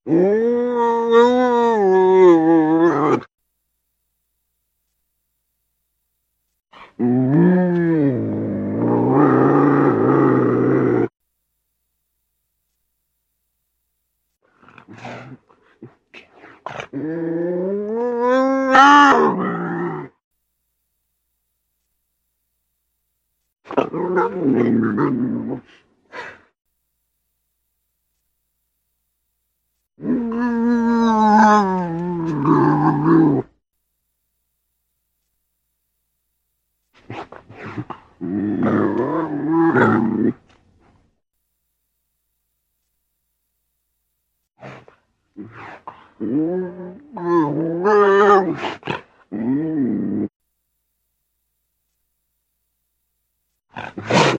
Звуки лесного кота
Здесь вы найдете реалистичные аудиозаписи: от мягкого мурлыканья до грозного рычания.
Дикая кошка вариант 2